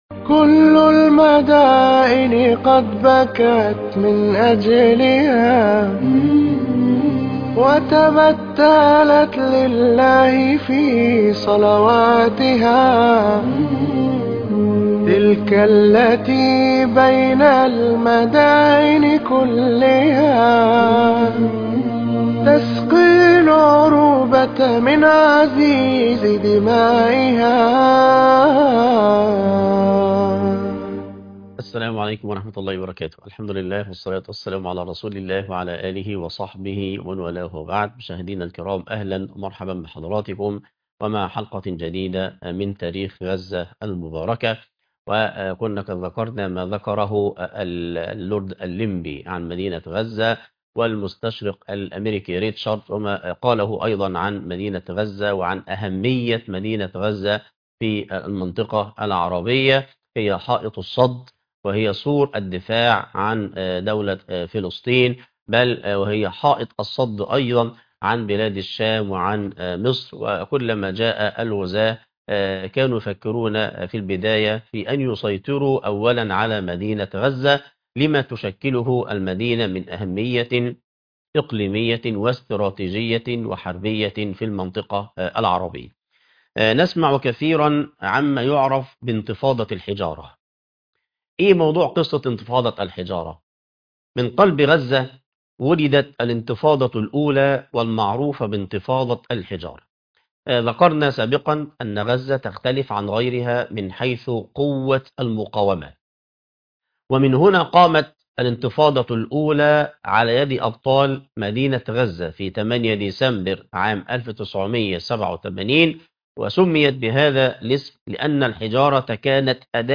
المقرأة - سورة مريم ص 307